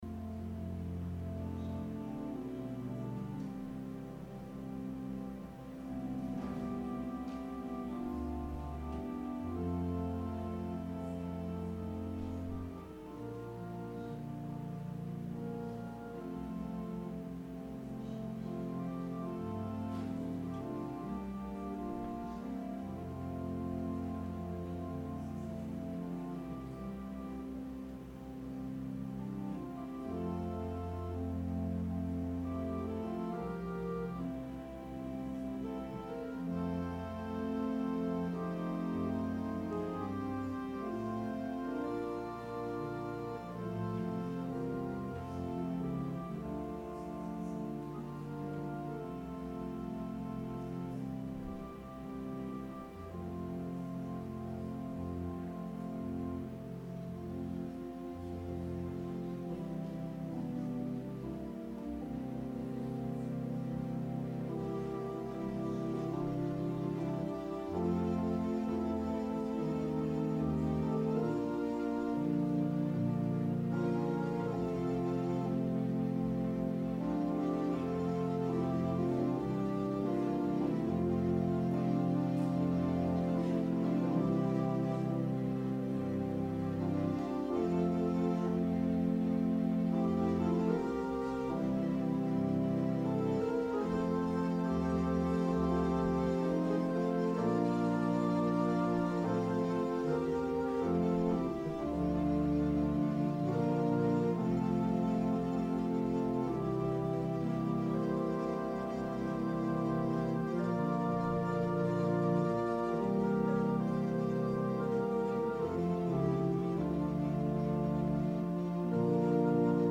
Sermon – July 7, 2019
advent-sermon-july-7-2019.mp3